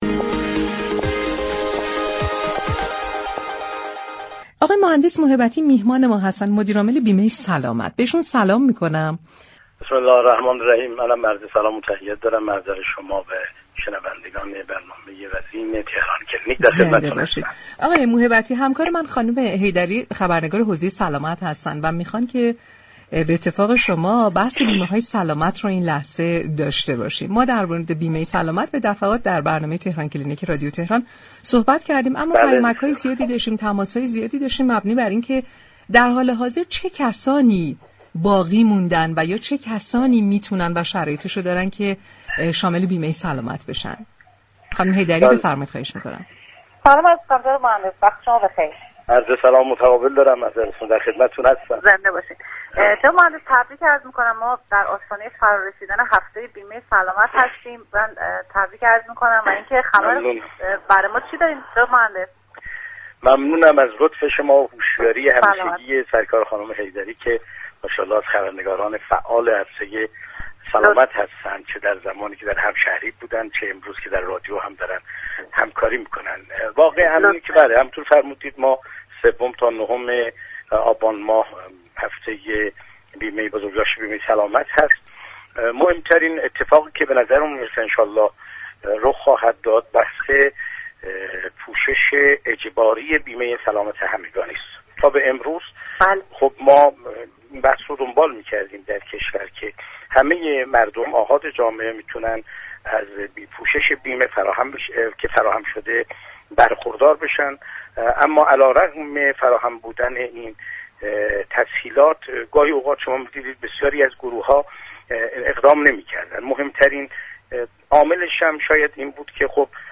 طاهر موهبتی، مدیرعامل سازمان بیمه سلامت در گفت وگو با رادیو تهران برنامه تهران كلینیك گفت: تمام افراد بیمه نشده و تمامی روستاییان و عشایر كه بیمه ندارند، تحت پوشش بیمه سلامت قرار می‌گیرند.